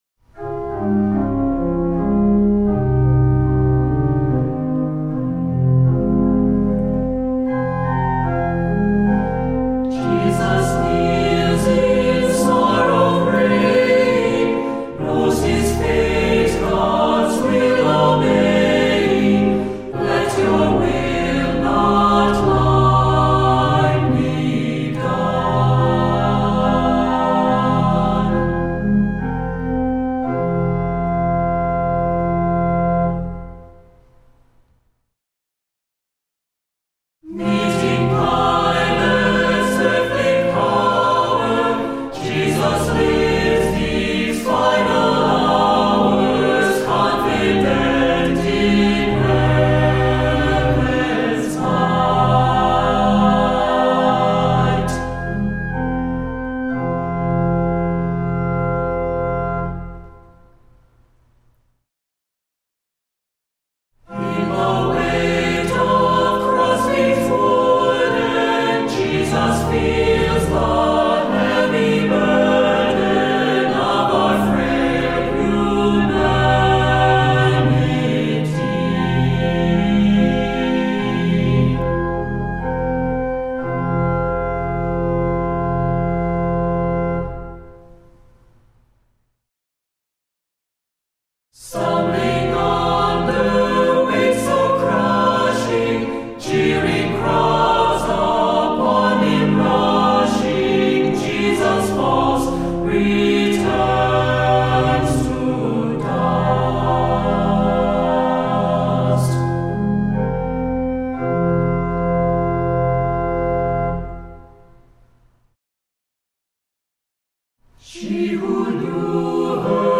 Voicing: Cantor,Assembly